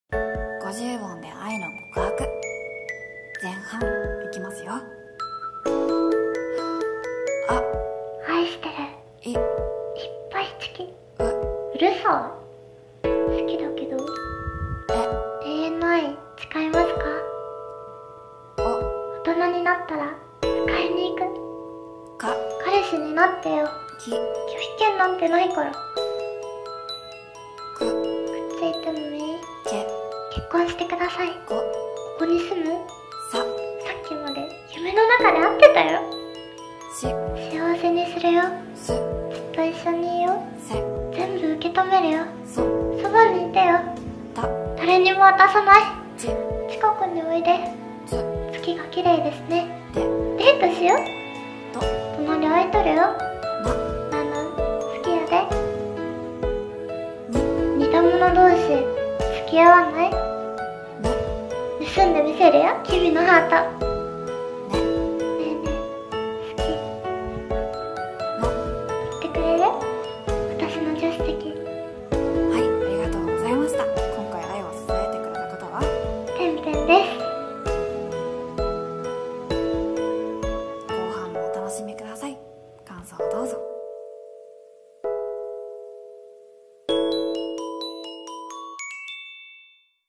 [声面接] 50音で愛の告白 [演技力]